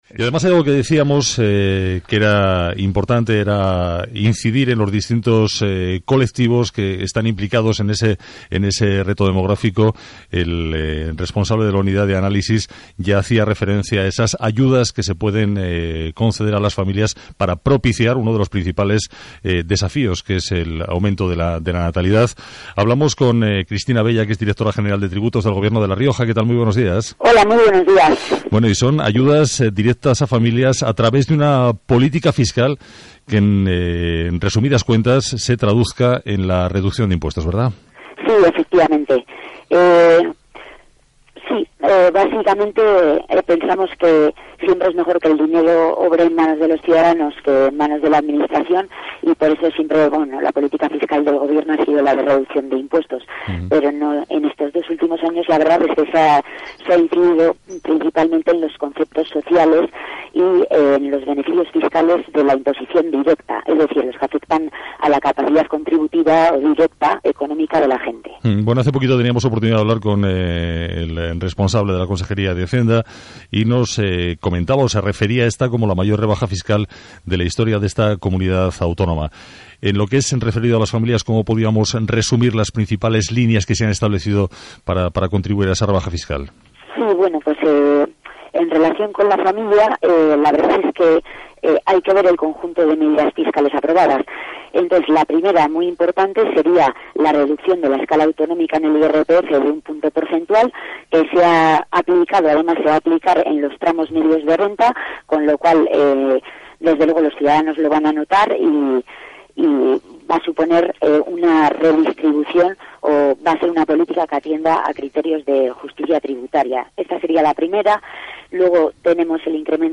Cristina Bella Gómez, Directora General de Tributos, enumera los beneficios fiscales implantados por el Gobierno de La Rioja alineados con el reto demográfico y dirgidios, en su mayor parte, a las familias, los jóvenes y los residentes en pequeños municipios.
Programa Hoy por Hoy Especial "Reto Demográfico", CADENA SER Radio Rioja, 27 de marzo de 2018.